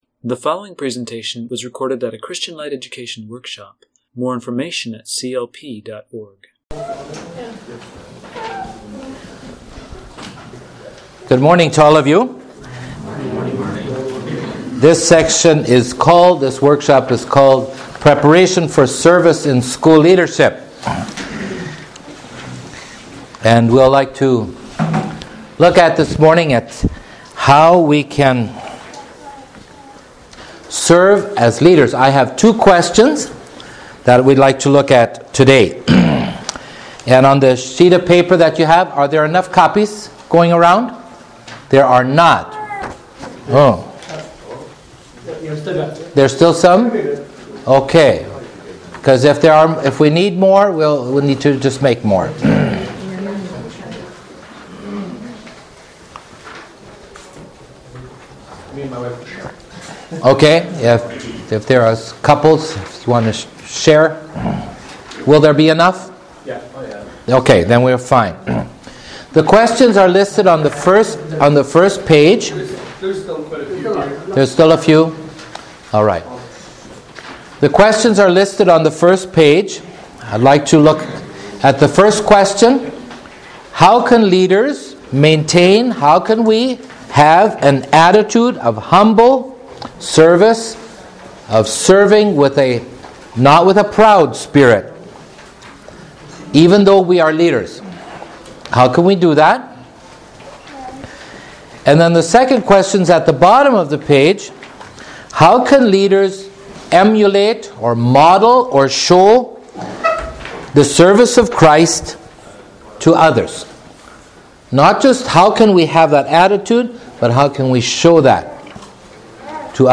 Home » Lectures » Preparation for Service-School Leadership